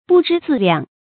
不知自量 bù zhī zì liàng
不知自量发音